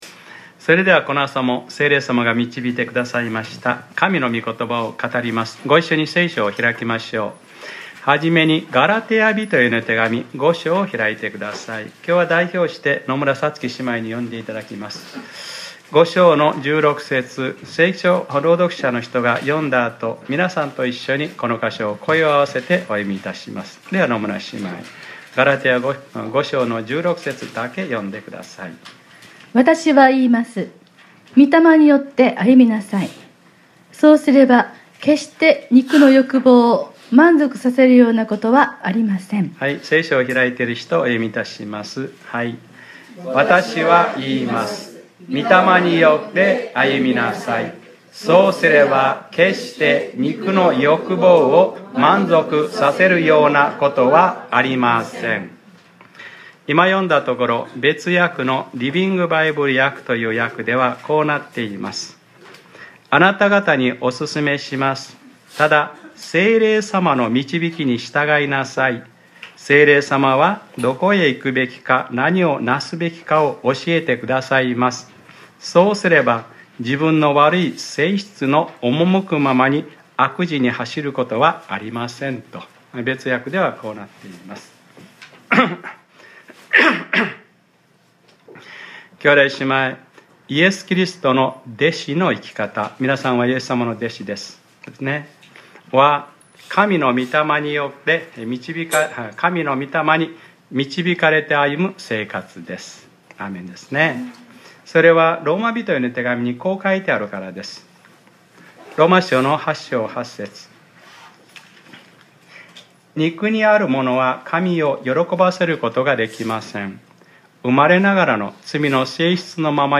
2018年02月04日（日）礼拝説教『御霊による歩み：３つの原則』